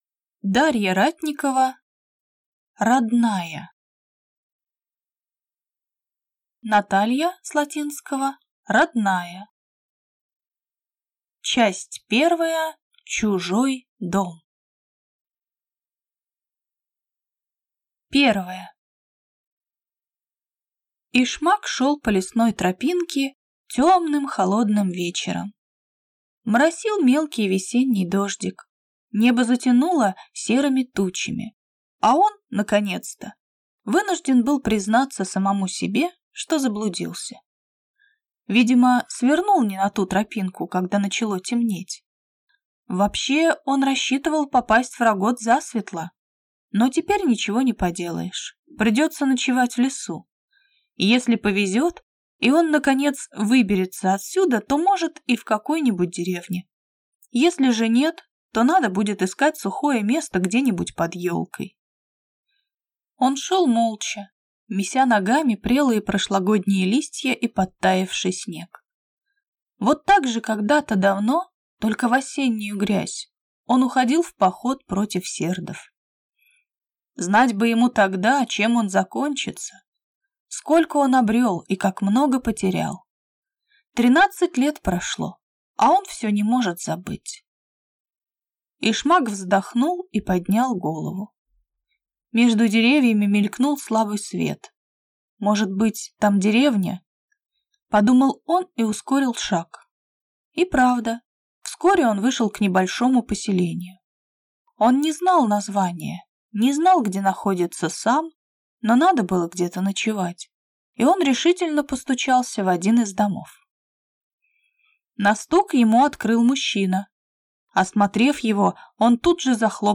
Aудиокнига Родная